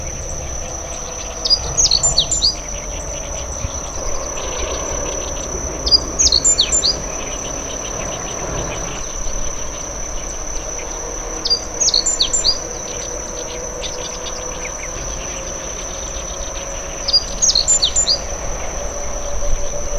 • コジュリン（ホオジロ科）